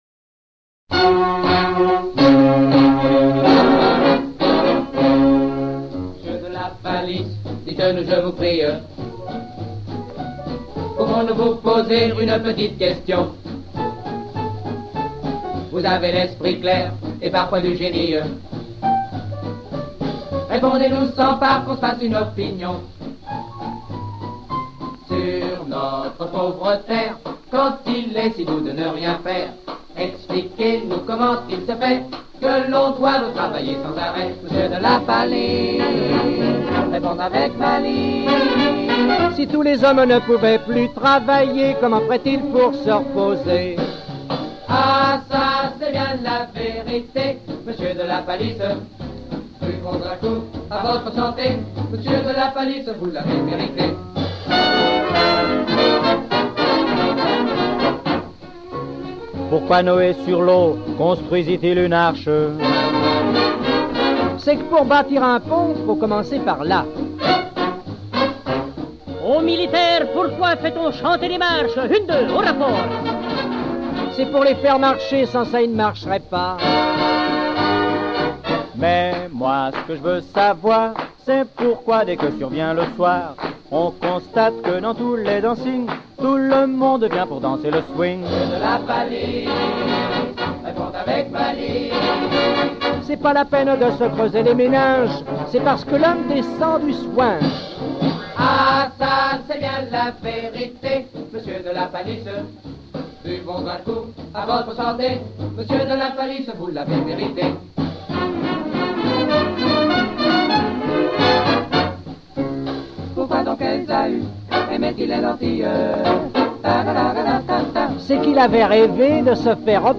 un big band qui « assure » avec des musiciens de qualité